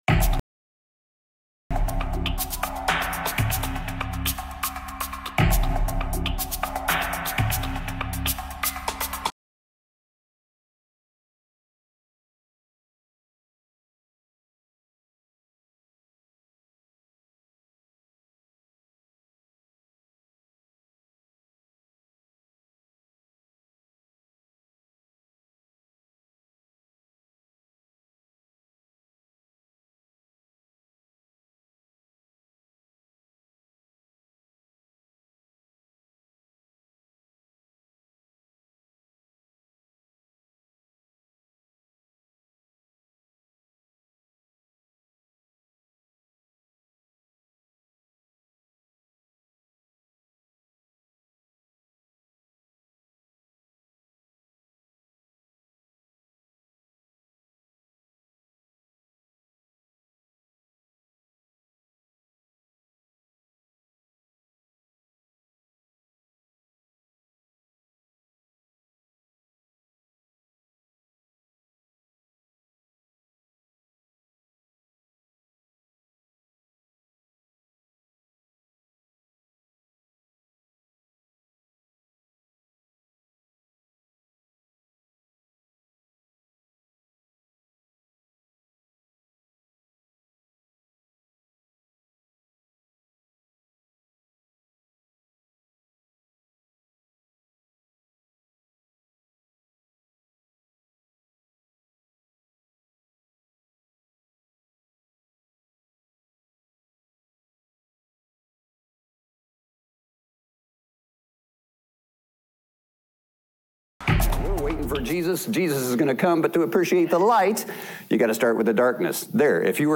audio-sermon-saved.m4a